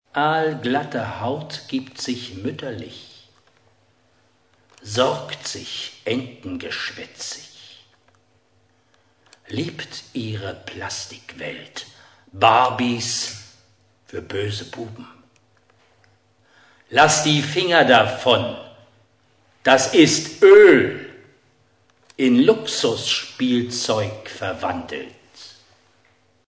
Die mit "Audioclip" gekennzeichneten mp3-Stücke enthalten kleine Ausschnitte aus dem literarisch-musikalischen Programm